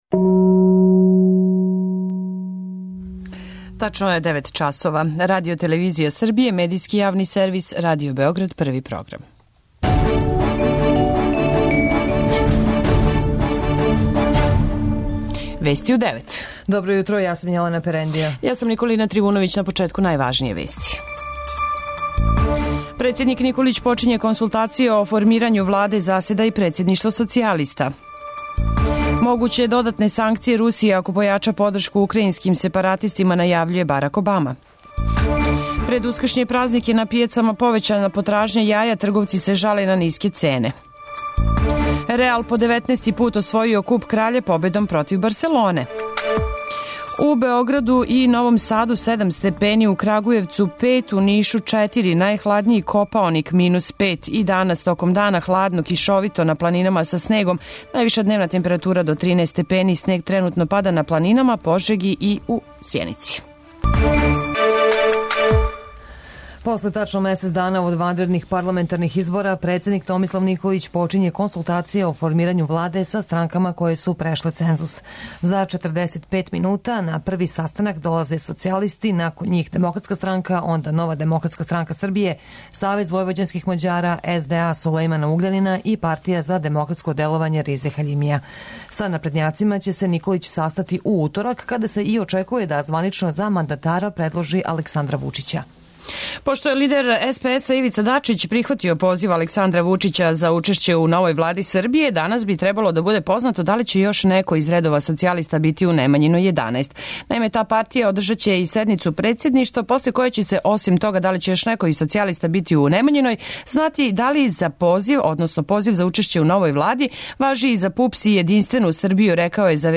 Уреднице и водитељке